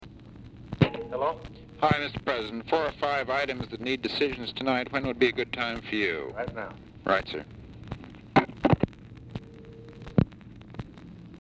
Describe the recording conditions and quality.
Format Dictation belt Location Of Speaker 1 Oval Office or unknown location